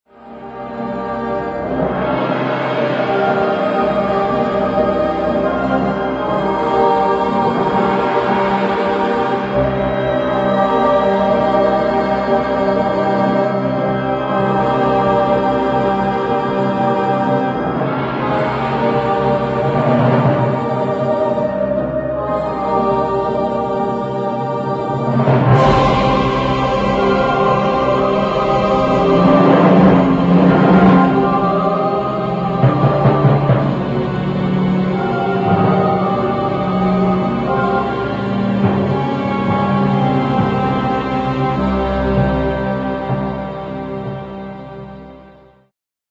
1990 thrilling slow instr.